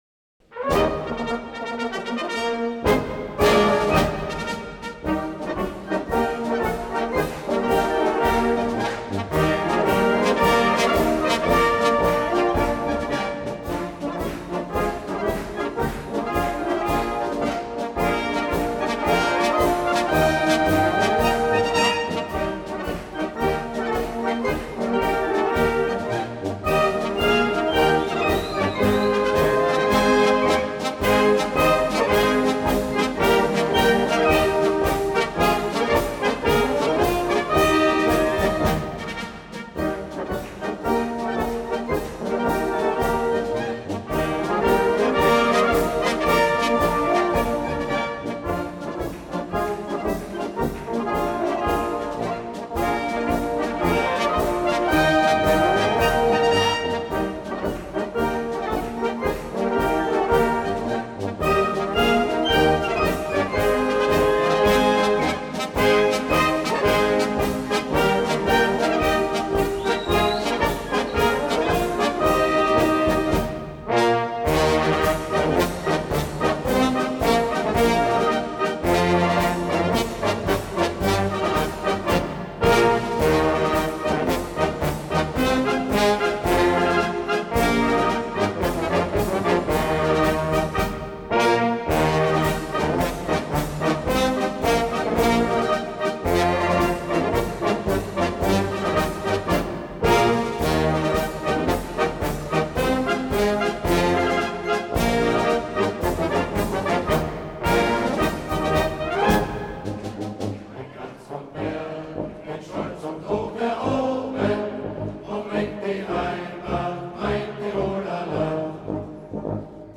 Marsch